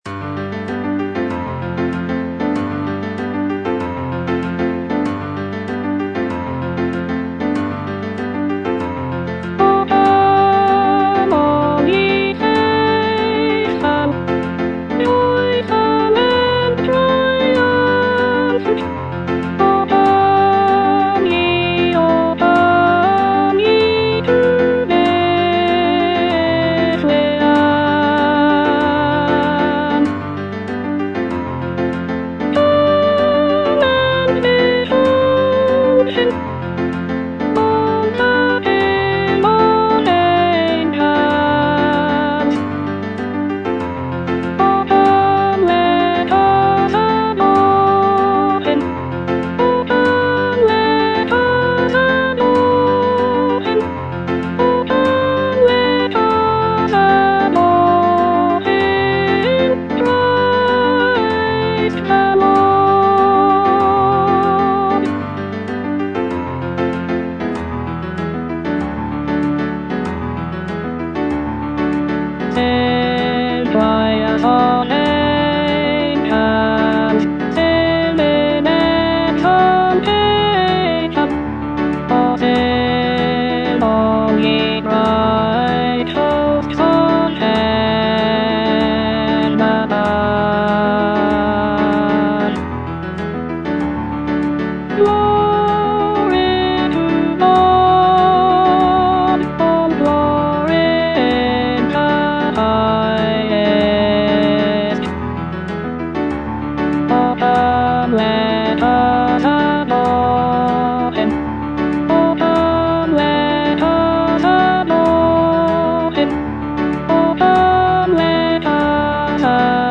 Alto (Voice with metronome)
Christmas carol